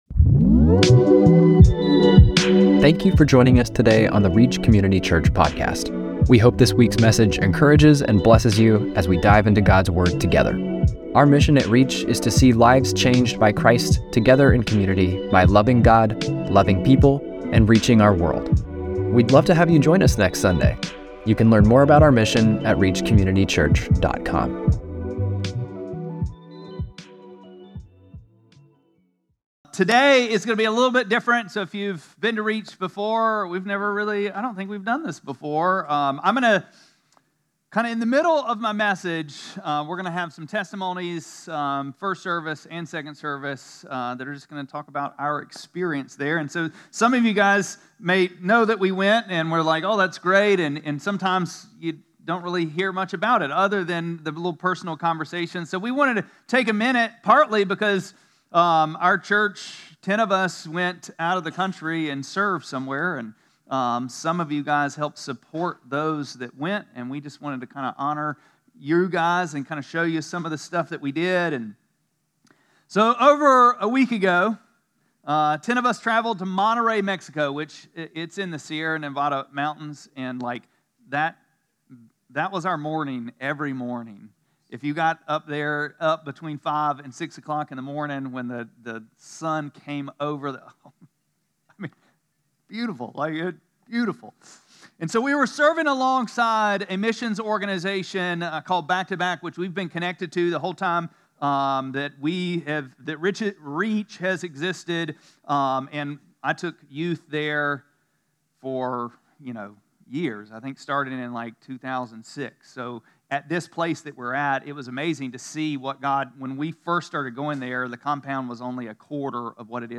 6-29-25-Sermon.mp3